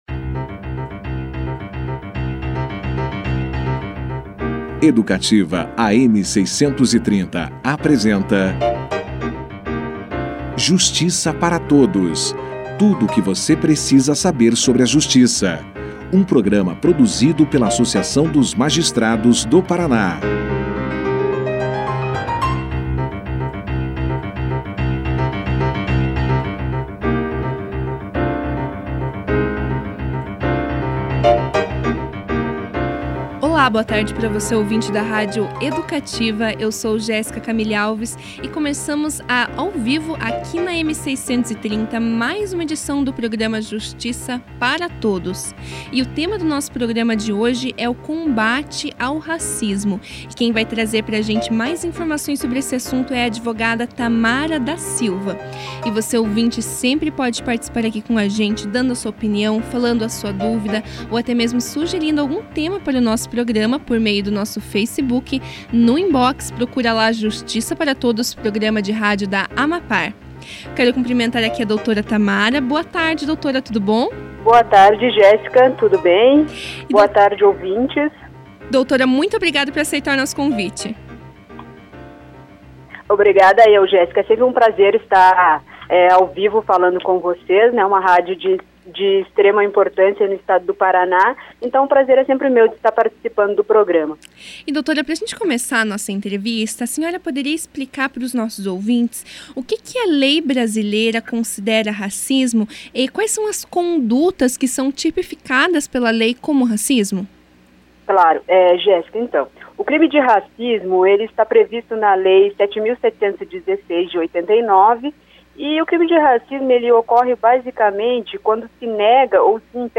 A advogada explicou, já no início da entrevista, o que a lei brasileira considera racismo. Além disso, falou do tema como problema social.